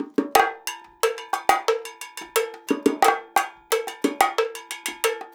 90 BONGO 4.wav